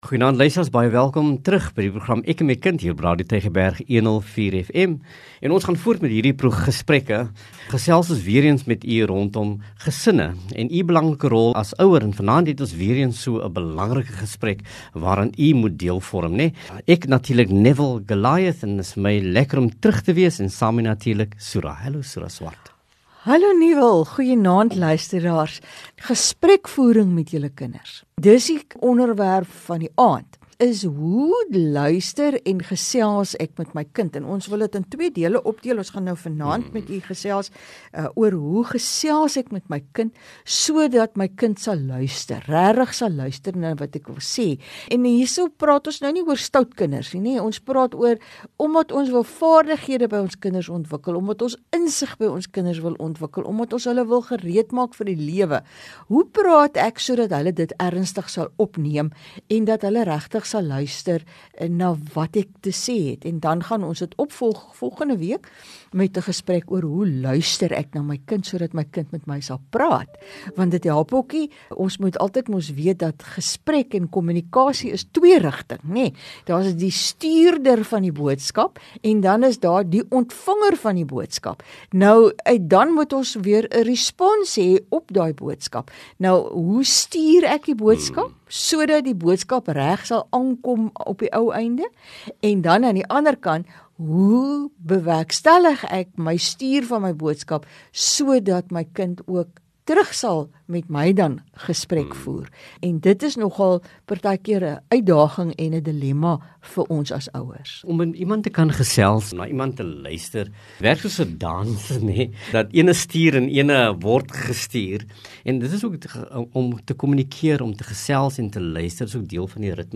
“Ek en my kind” is gespreksprogram wat vanuit ‘n onderwysperspektief ouerleiding bied ten opsigte van kinderontwikkeling en kindersorg. Dit gee ouers perspektief oor Onderwyssake en brandpunte wat impak kan hê op hul rol en verantwoordelikheid ten opsigte van hul kind se welstand in die klaskamer en op die speelgrond.